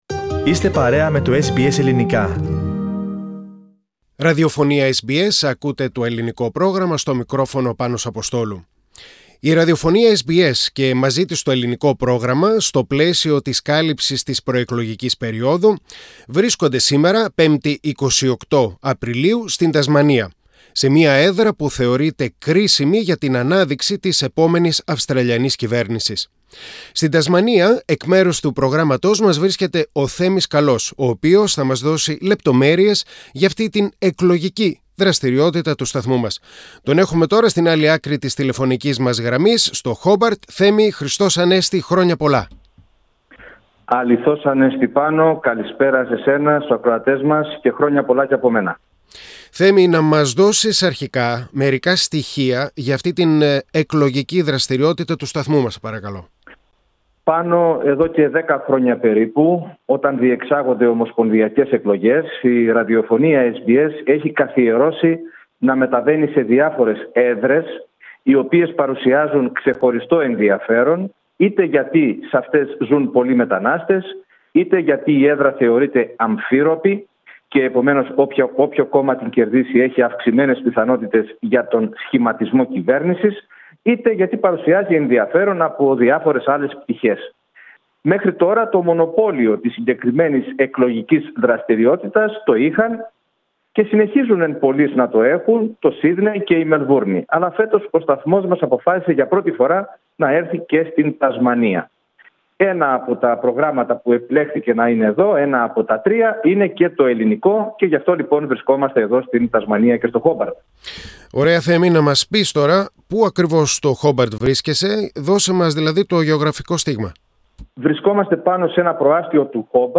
Η Ραδιοφωνία SBS στο πλαίσιο της κάλυψης της προεκλογικής περιόδου, βρίσκεται αυτές τις μέρες στην Τασμανία.